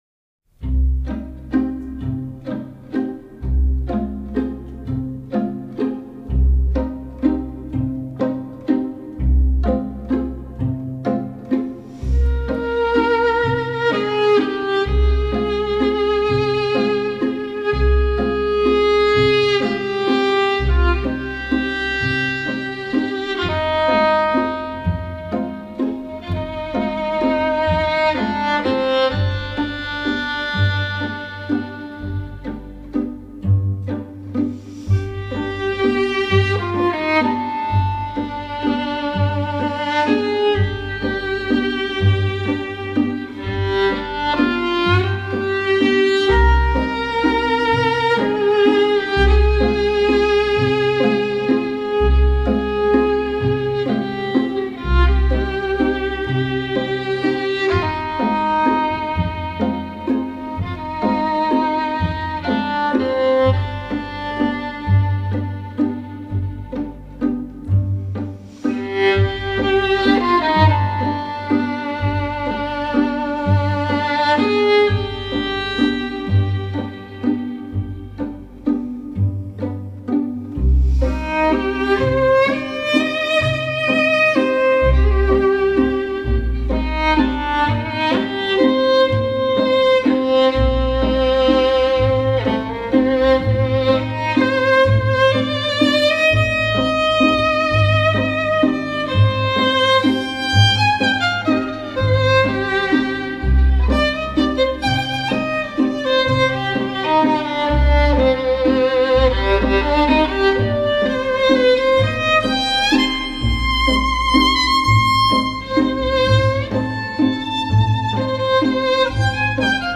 Genre: New Age, Violin